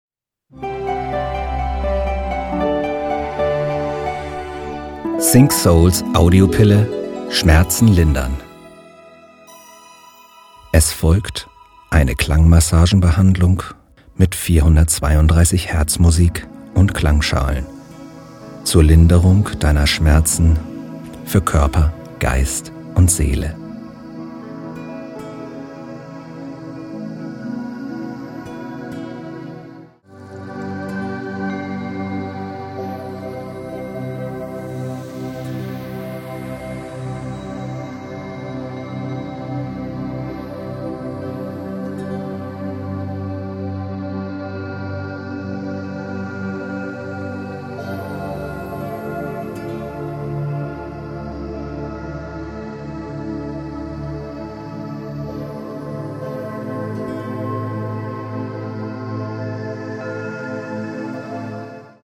432 Hz Musik & Klangschalen
Klang pur